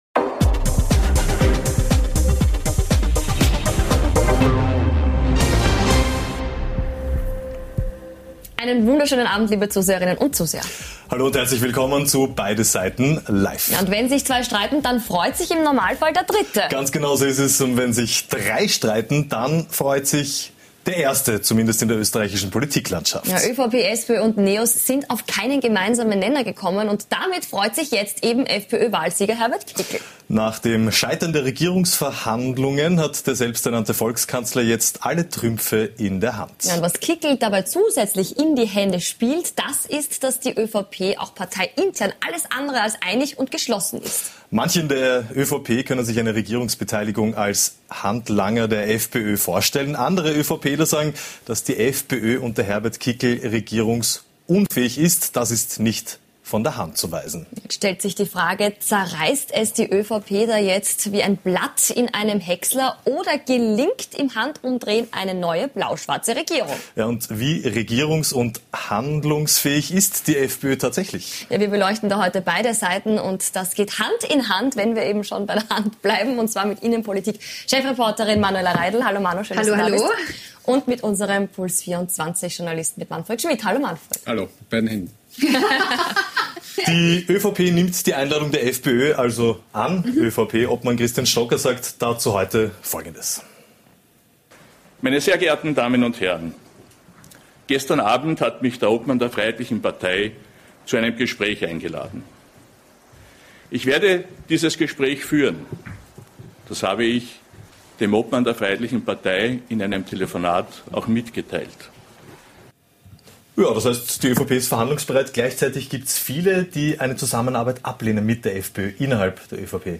Beschreibung vor 1 Jahr Wir beleuchten heute beide Seiten zu folgenden Themen: Kann die ÖVP Juniorpartner? Welche Freiheitlichen könnten in die Regierung einziehen? Nachgefragt haben wir heute bei Werner Kogler, Parteichef Die Grünen.